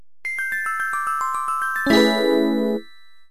Samsung Ringtones